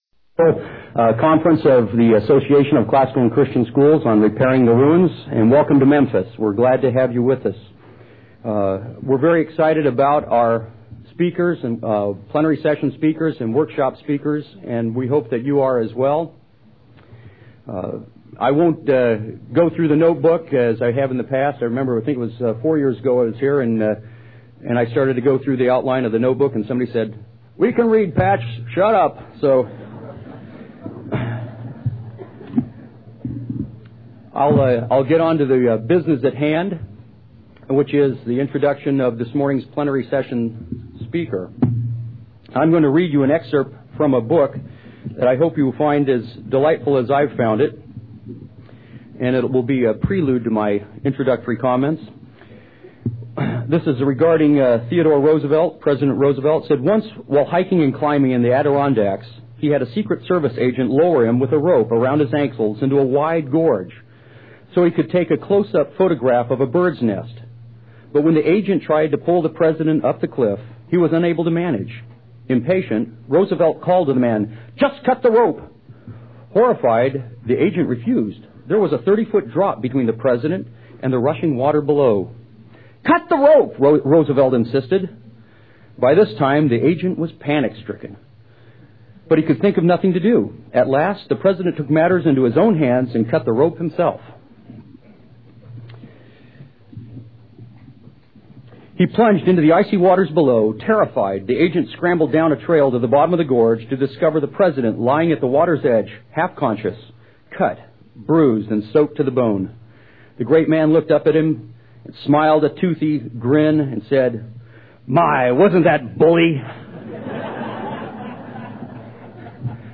2001 Foundations Talk | 0:48:33 | All Grade Levels, Leadership & Strategic
The Association of Classical & Christian Schools presents Repairing the Ruins, the ACCS annual conference, copyright ACCS.